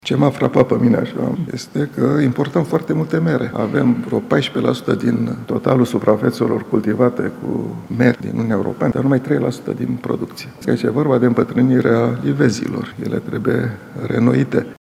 Declarația a fost făcută la prezentarea Raportului asupra inflaţiei de zilele trecute.